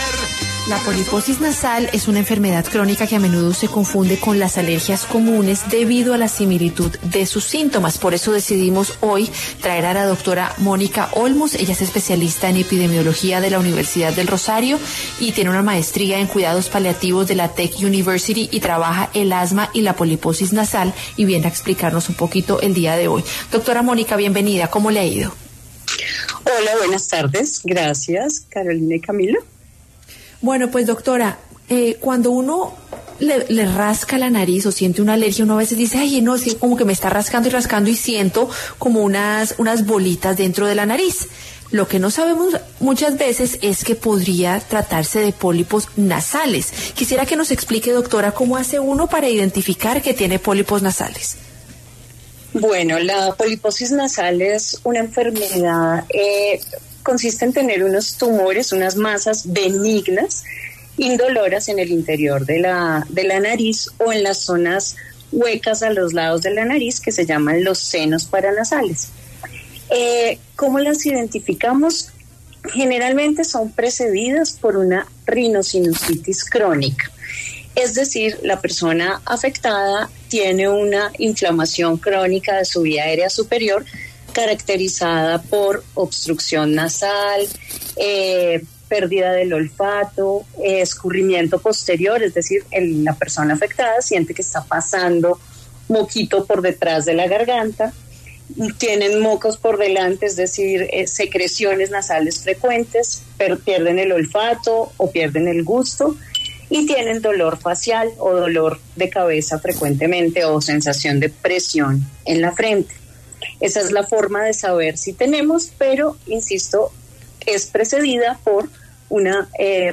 explicó en los micrófonos de Salud y Algo Más de qué se trata esta enfermedad crónica y por qué se suele confundir con la rinitis.